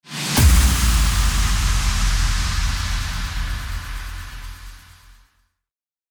FX-1859-IMPACT
FX-1859-IMPACT.mp3